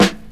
• 00s Dry Hip-Hop Snare Drum Sound G Key 393.wav
Royality free snare one shot tuned to the G note. Loudest frequency: 1392Hz
00s-dry-hip-hop-snare-drum-sound-g-key-393-jHR.wav